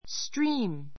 stríːm